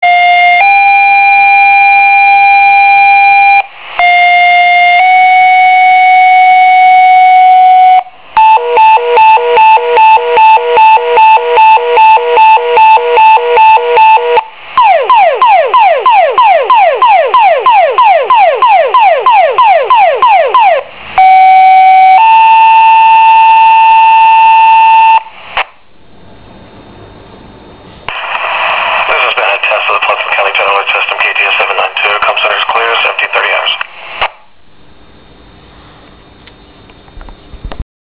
pager.wav